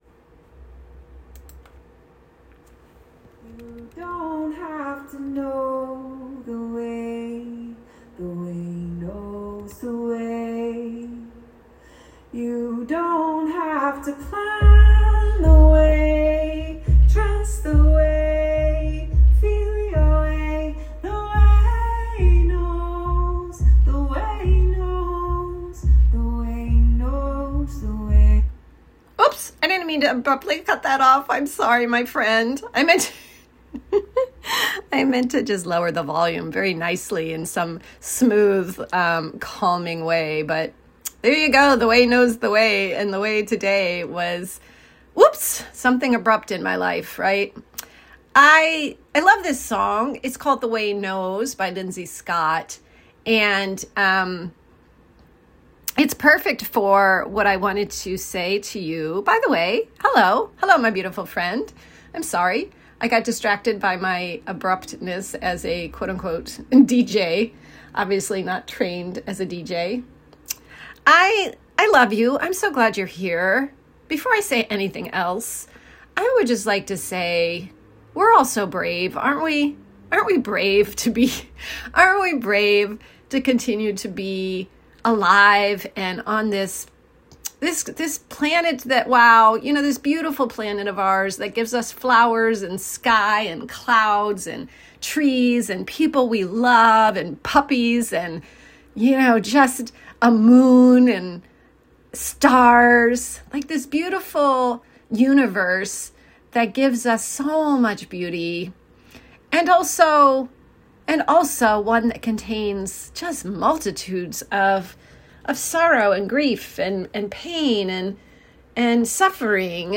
I had walked into the studio to make your recording, but my mind was blank!